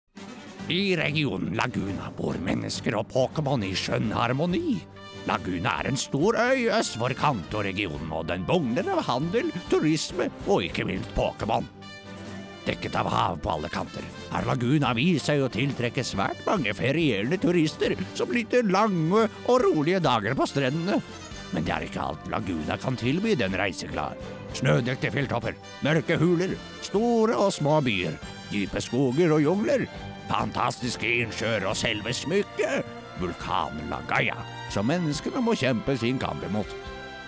uma voz barítono norueguesa, profunda e acolhedora
Animação
Mic: Shure SM7B + Trtion Audio Fethead Filter
BarítonoProfundoBaixo